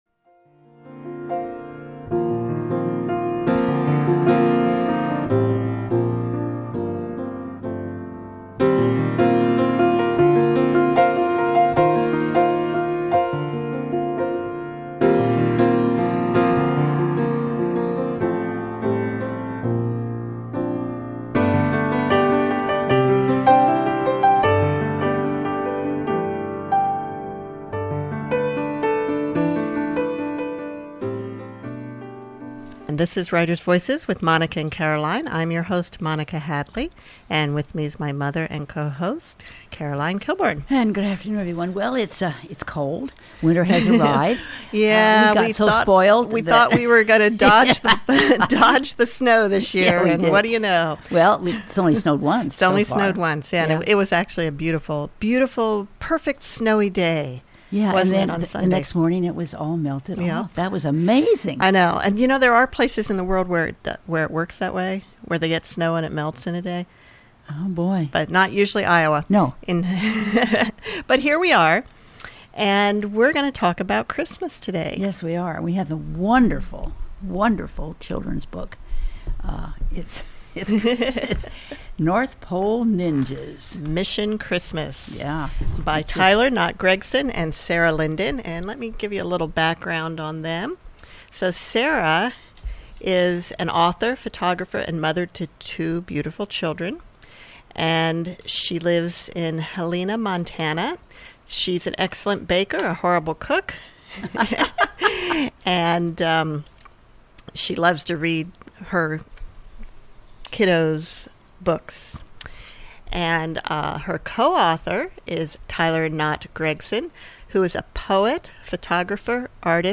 Christmas interview